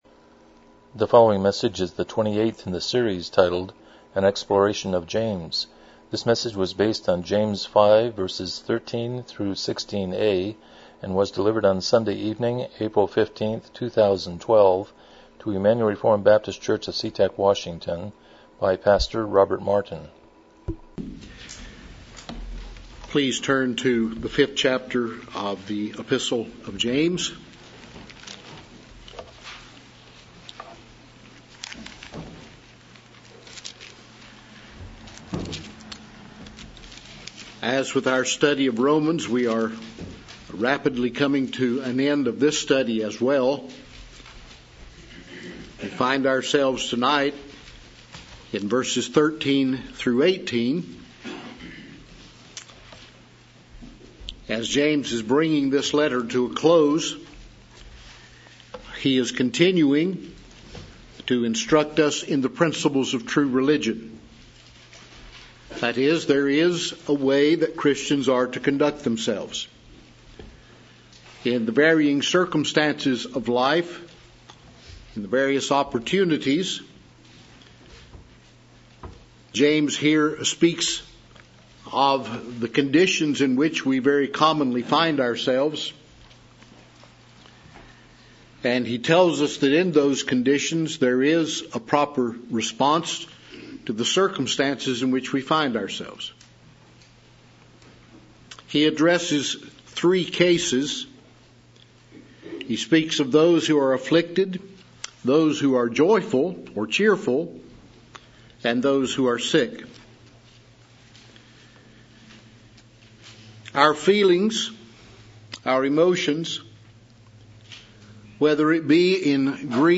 James 5:13-16 Service Type: Evening Worship « 166 Romans 16:20b